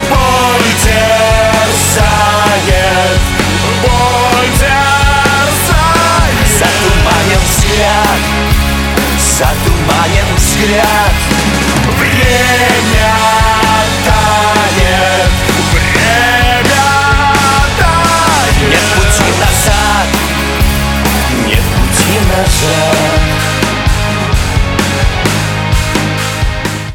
• Качество: 192, Stereo
Новая великолепная песня в стиле рок